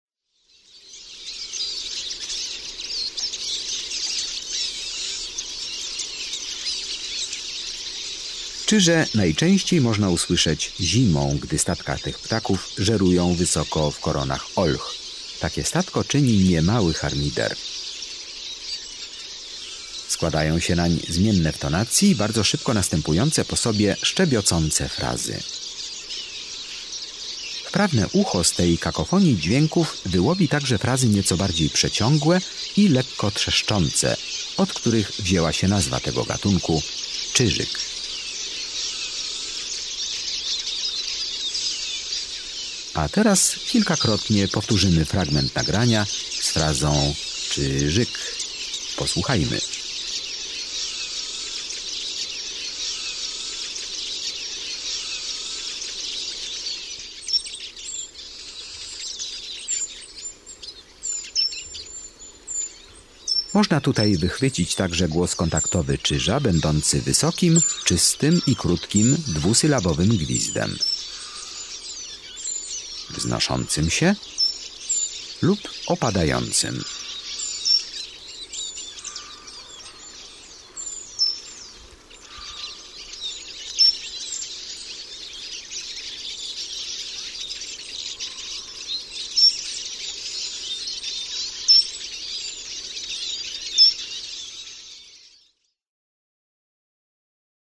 30 CZYŻ.mp3